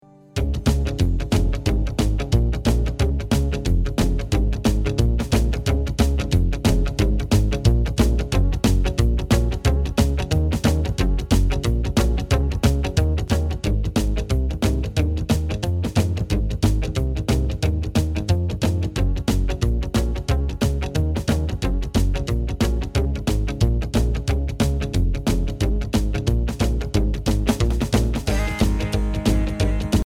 Listen to a sample of the instrumenal track.
Downloadable Instrumental Track